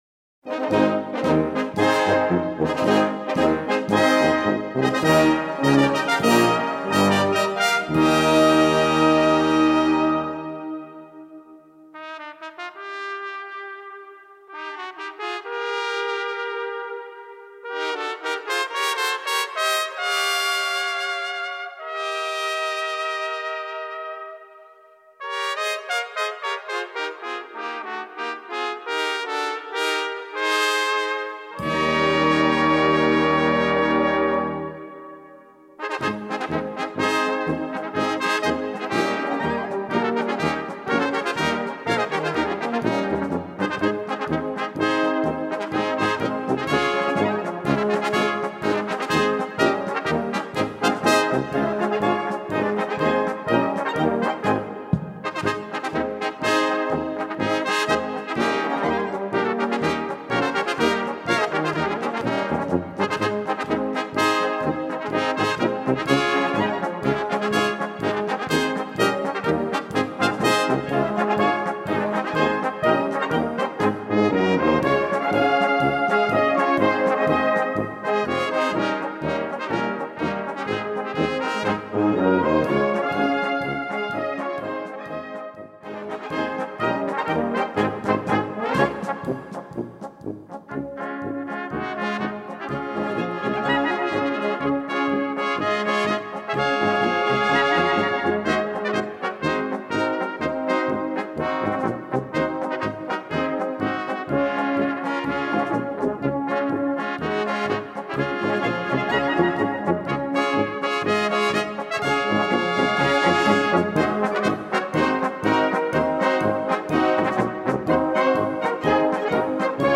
Gattung: Solopolka für drei Trompeten
Besetzung: Blasorchester